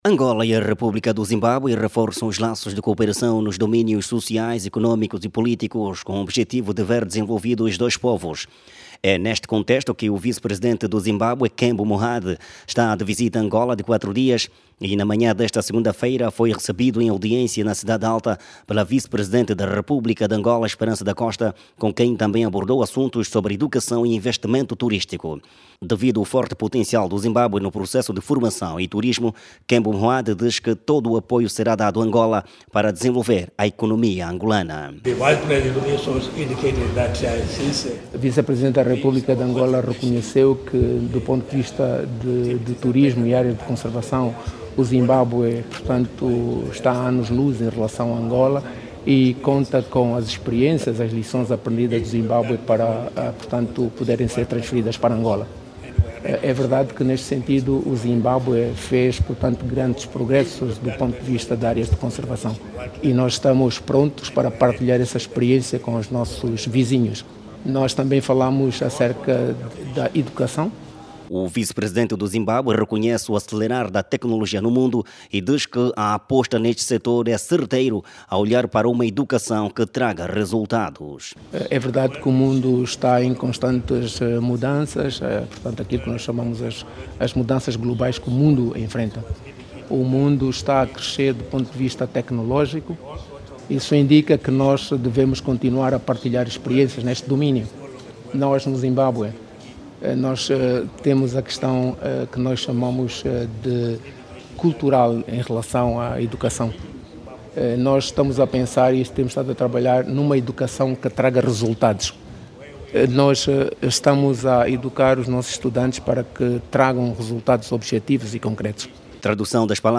No centro da conversa estiveram assuntos ligados a economia, Turismo e Educação. Jornalista